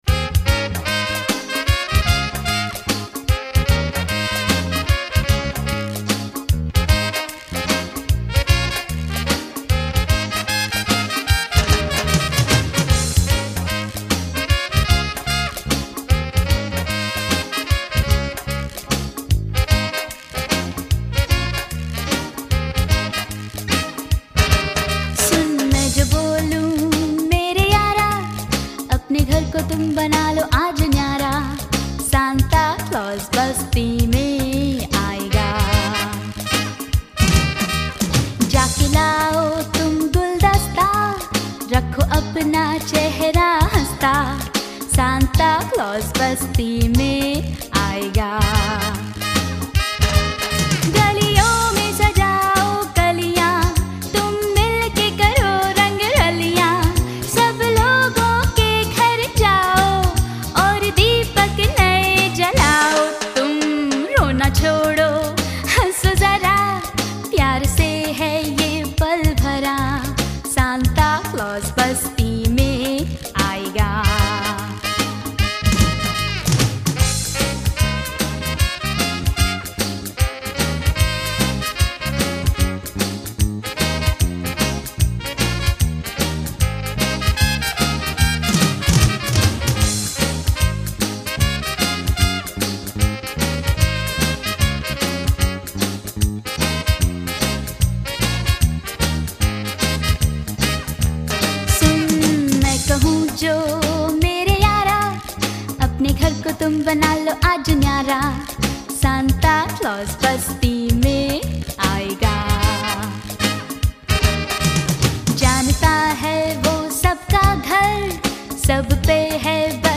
These are Christian audio songs in Hindi and Pahari languages.